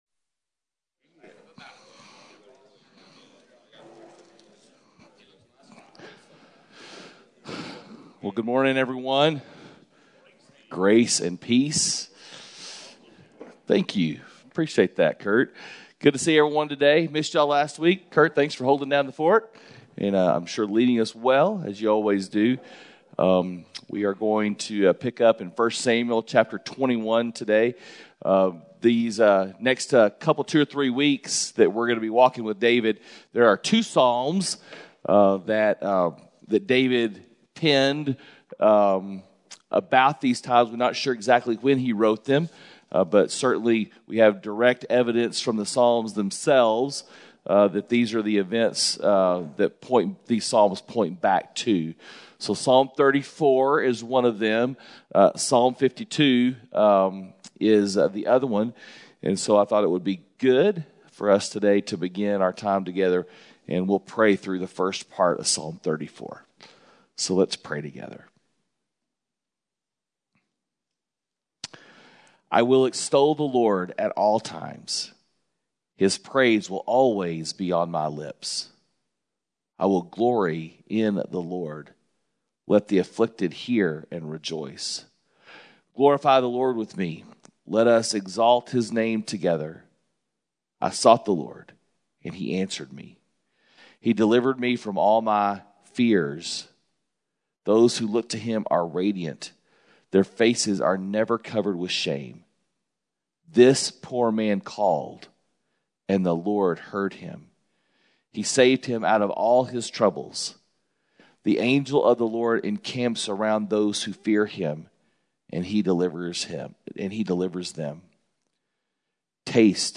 Men’s Breakfast Bible Study 4/20/21
Mens-Breakfast-Bible-Study-4_20_21.mp3